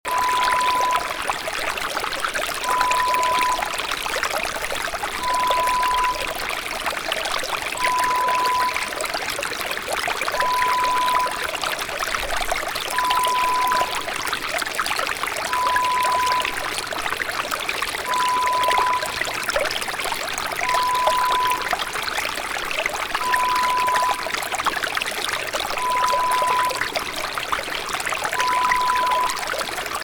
Mountain Stream - Babbling Brook - Water 05.Wav Sound Effect
(0:59)MOUNTAIN STREAM, BABBLING BROOK, WATER: This true-stereo water sound effect is delivered instantly in CD quality WAV format (preview contains a security watermark tone).
PREVIEW = Lo-Fi mp3 with pink tone security watermark (beep).
We only offer high quality, stereo recordings!
Babblingbrook05_60sec_SoundeffectSAMPLE.mp3